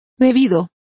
Complete with pronunciation of the translation of drunkest.